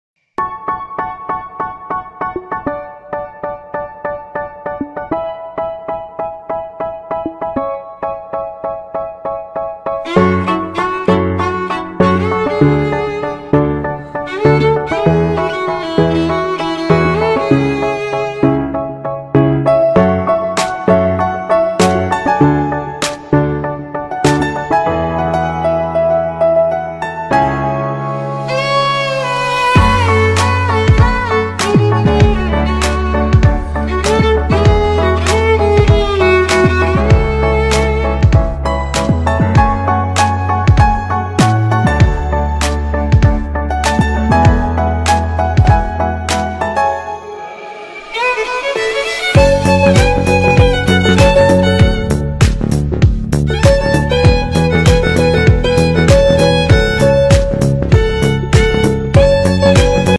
• Качество: 128, Stereo
без слов
красивая мелодия
Cover
инструментальные
пианино
Классный инструментальный кавер